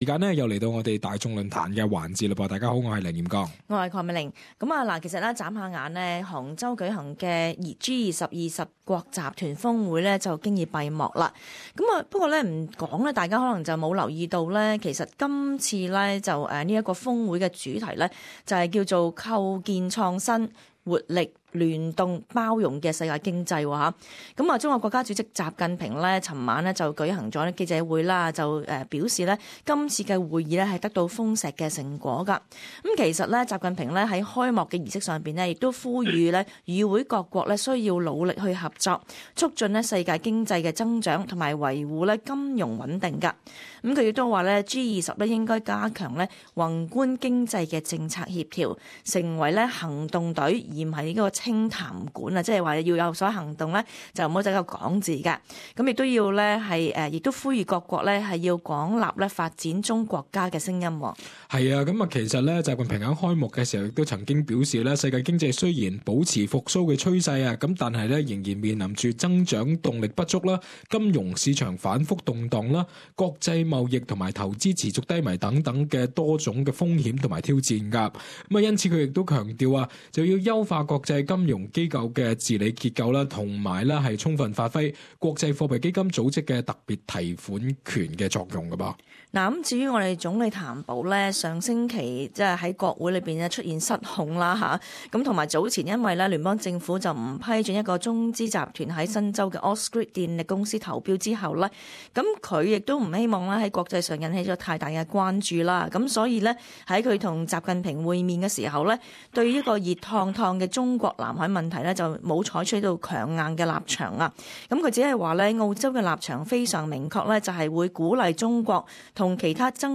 在杭州举行的G-20二十国集团峰会转眼经已闭幕, 在今天的【大众论坛】，我们与听众讨论： 你认为在今次峰会中，澳洲究竟可以获得甚么益处呢？